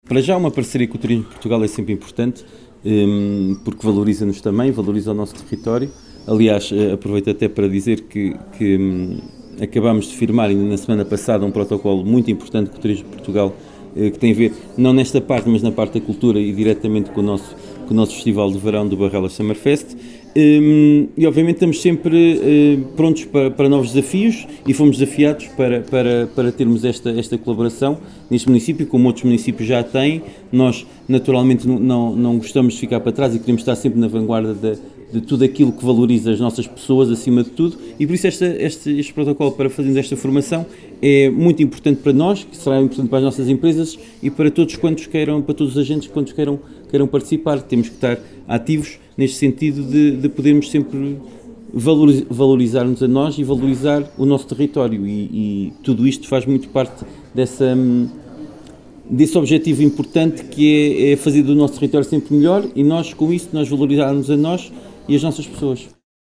Na manhã desta quinta-feira, 24 de julho, foi assinado no Salão Nobre dos Paços do Concelho de Vila Nova de Paiva um protocolo de colaboração entre o Município e o Turismo de Portugal, no âmbito do programa “Formação + Próxima” que visa a realização de cursos de formação, onde as empresas locais poderão ter um papel ativo no sentido de ver os seus colaboradores certificados profissionalmente nas áreas de Hotelaria e Turismo.
Paulo Marques, Presidente do Município de Vila Nova de Paiva, falou da importância da assinatura deste protocolo e da parceria com o Turismo de Portugal no que à formação profissional diz respeito. Refere ainda que uma parceria com o Turismo de Portugal é sempre importante.